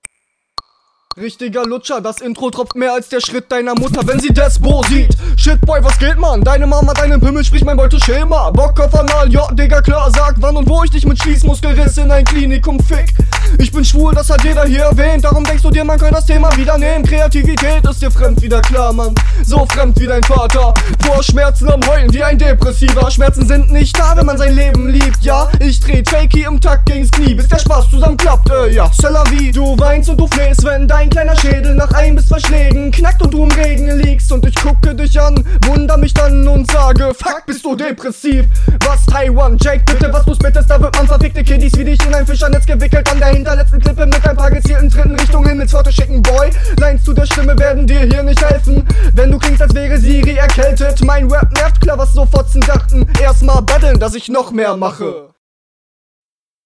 Mische Flow und Text sind hier deutlich besser.